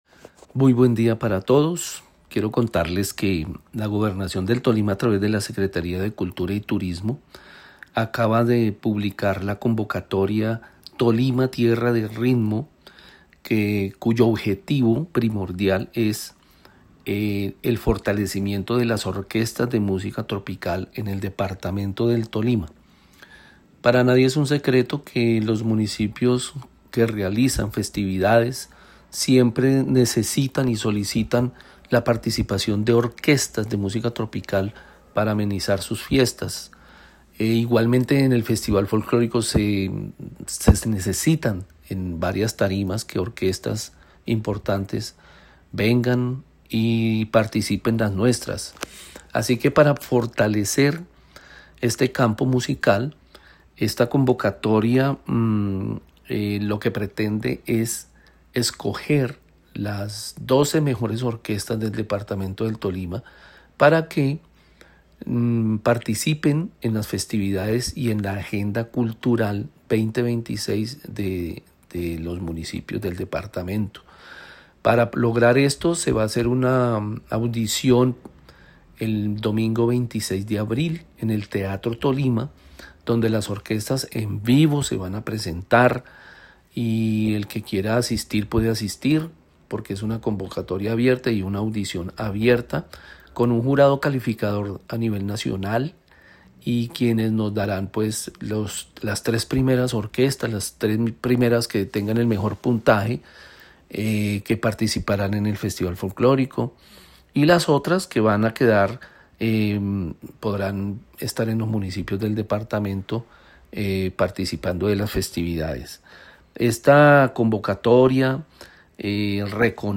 AUDIO-NOTICIA.mp3